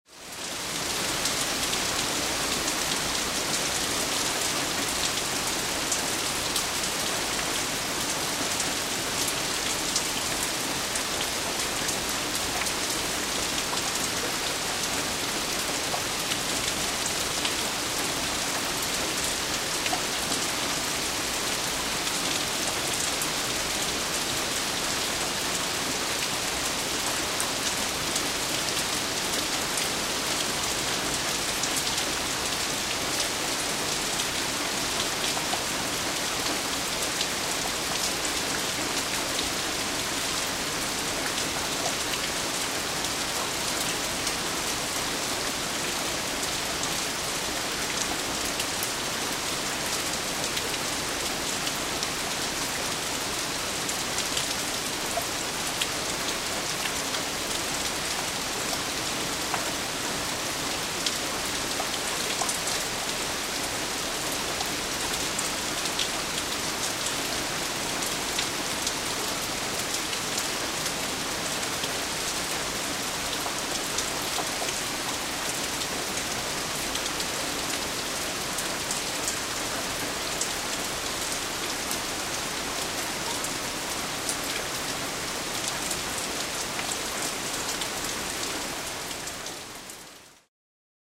Звуки погоды
Дождь над водой